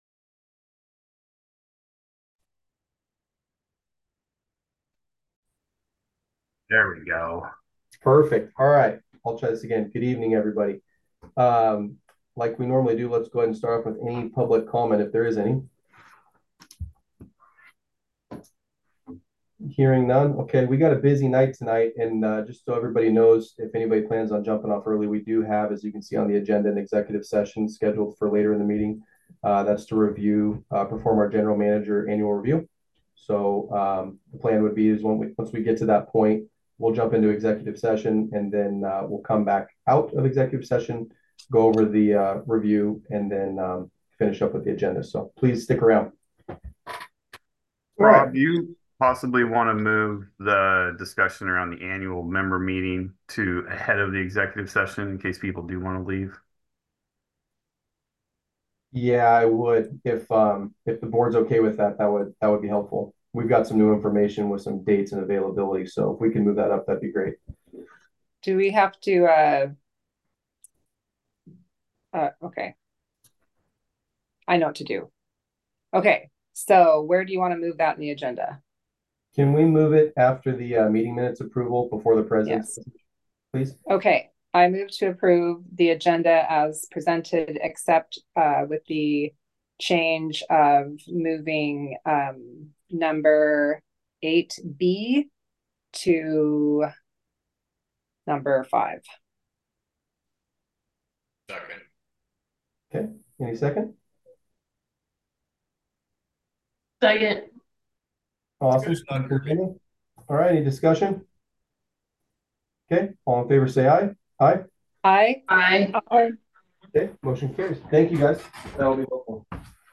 Board Monthly Meeting
Scappoose Drainage Improvement Company (SDIC) Board of Directors will hold a monthly meeting on March 7, 2024 at 6:00 p.m. PT. Due to COVID-19 and resulting protocols, this meeting will not be held in person, but virtually through Zoom.